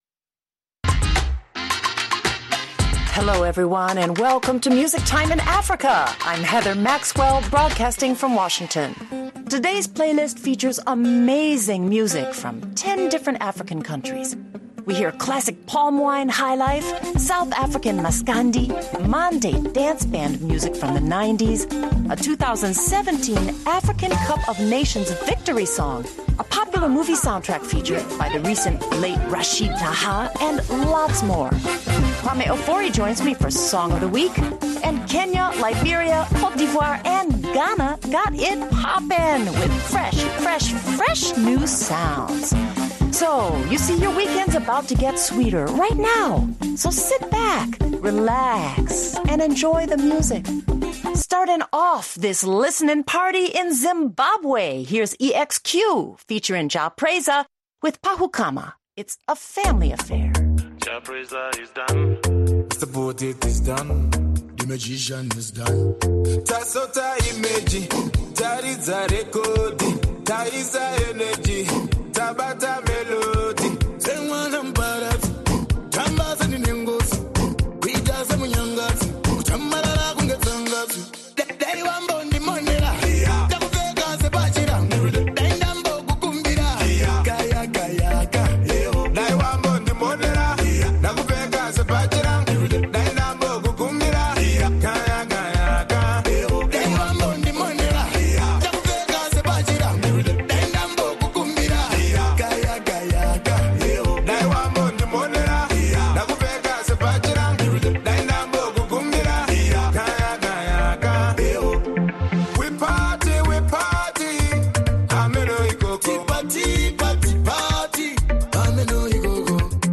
Listen to the soundtrack of African millennials’ all-time favorite African songs.
And then it’s back to more African millennial hits that will groove and sway you to the end of the hour.